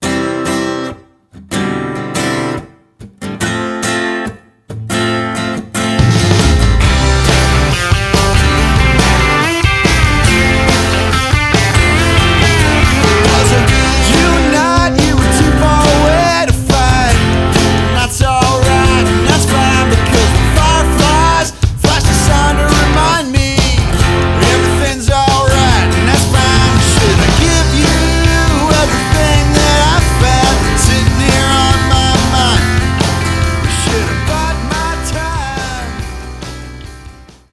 After Mastering:
Rock/Country